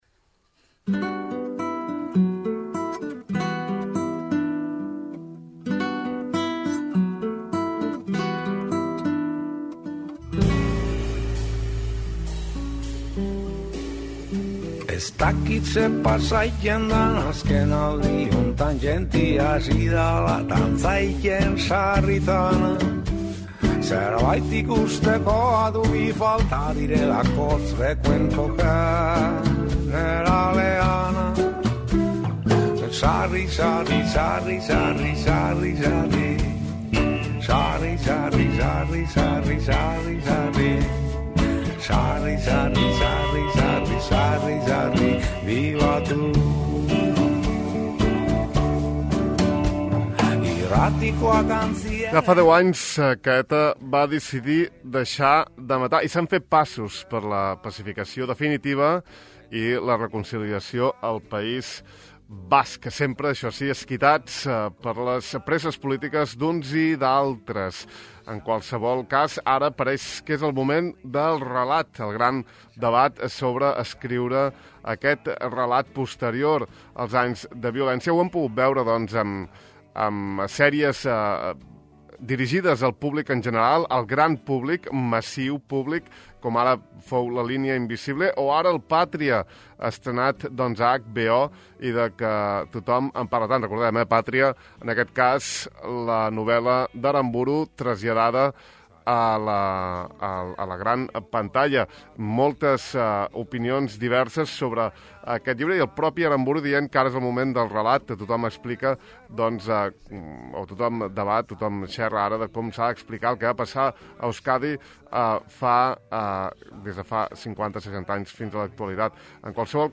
Aprofitant l’avinentesa hem entrevistat a Aintzane Ezenarro, directora de l’Institut de la Memòria, la Convivència i dels Drets Humans del Govern Basc, Gogora, que va néixer amb la missió de preservar i transmetre la memòria democràtica del País Basc.